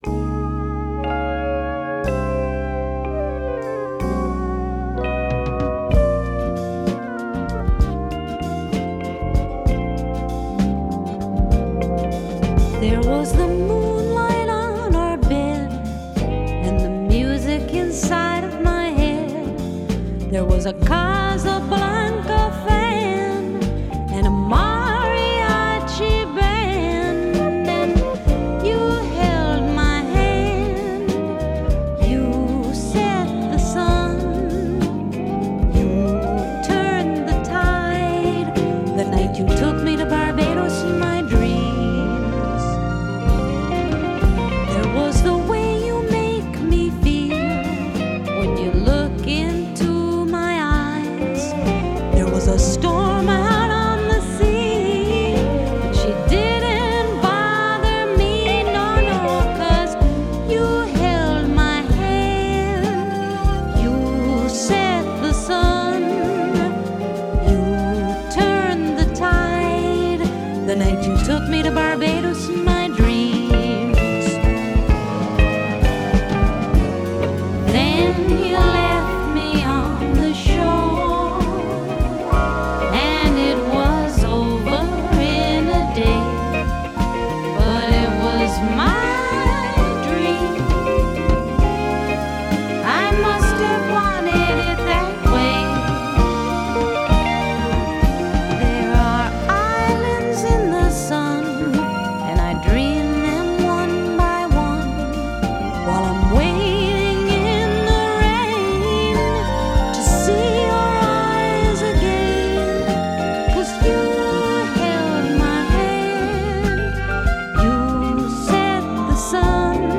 Genre: Pop, Folk, Rock